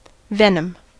Ääntäminen
US
IPA : /ˈvɛnəm/